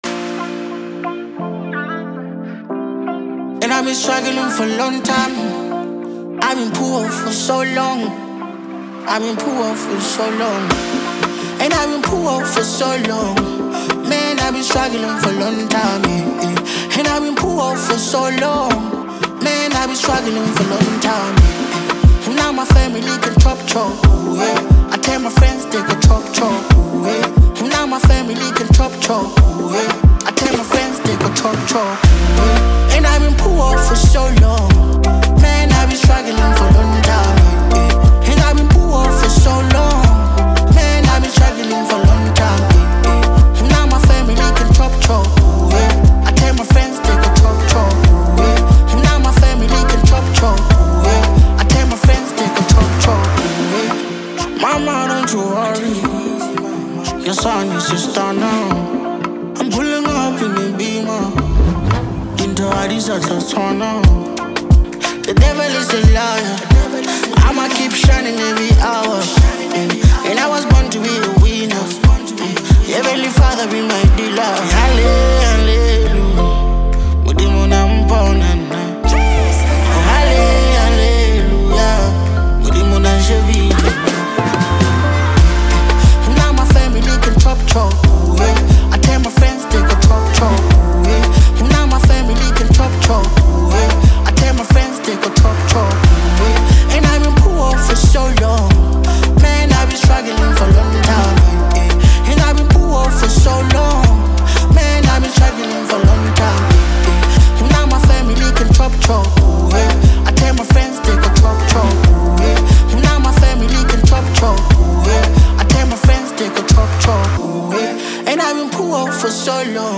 this awesome South African singer-songwriter and music star
” which is a collection of seven incredible Hip Hop tracks.
It has got everything from catchy beats to heartfelt lyrics.